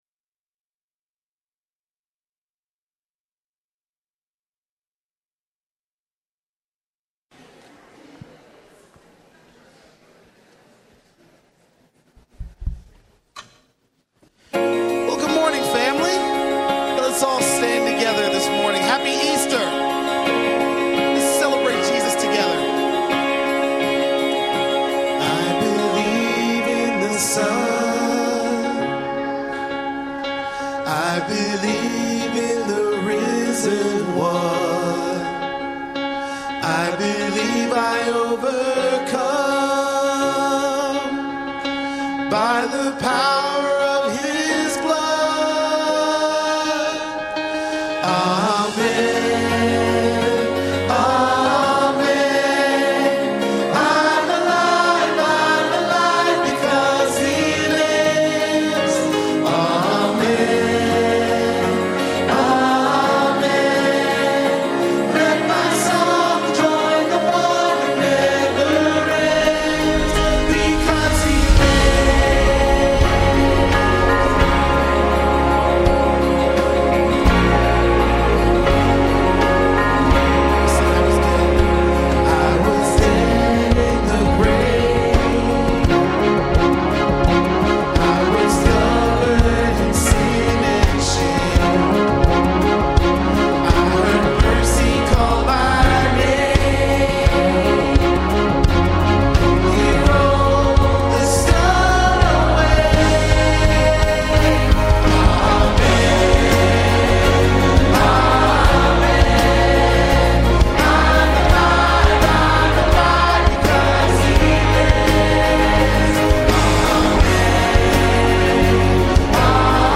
Our message Easter Sunday is titled “Because He Lives”. As we will look at the selected scripture in John 14:15-31, Jesus was preparing to be with the Father.